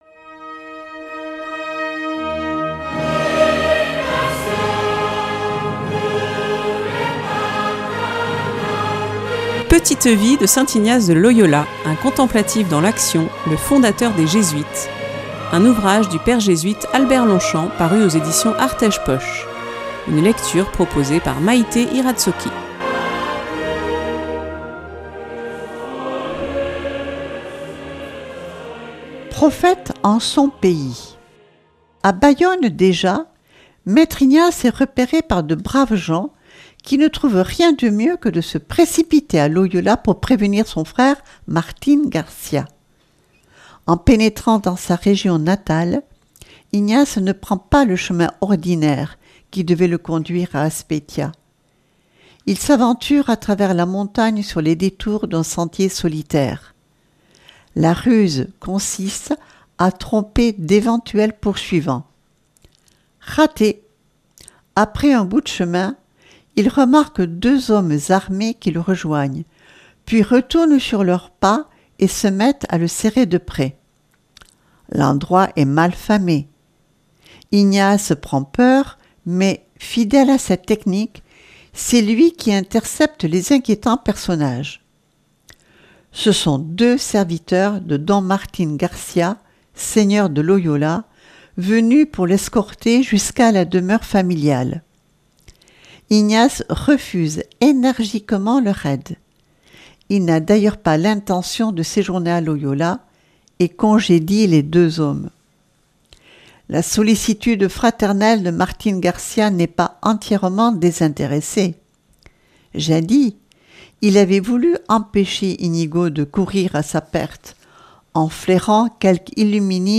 Une lecture